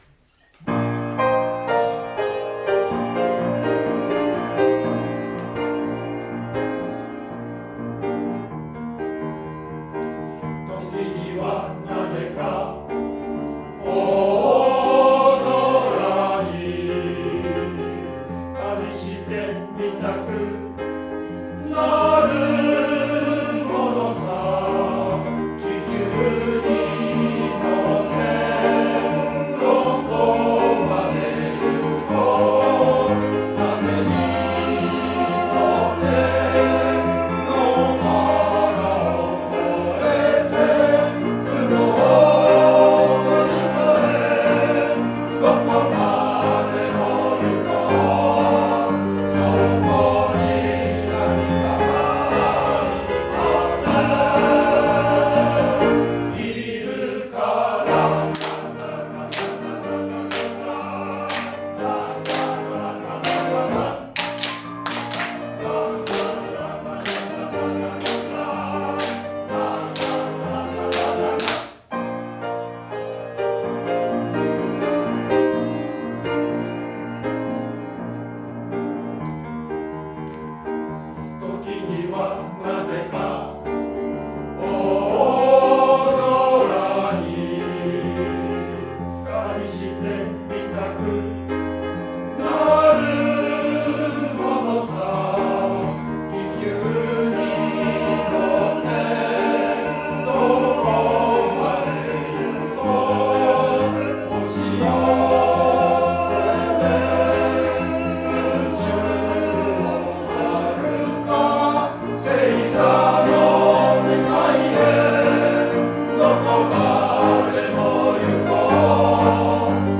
合唱祭が近づく、東小での強化練習
合唱祭が近づき今回は強化練習で、合唱祭で歌う２曲を、時間をかけて練習しました。